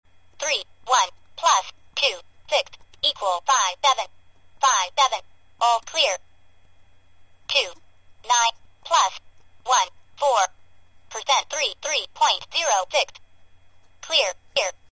• Features female voice, and 8-digit display read out digit by digit.
This eight-digit talking calculator's perky female voice speaks each key you press as you're entering a calculation, and the answer is read out digit-by-digit.
talking_pocket_calculator.mp3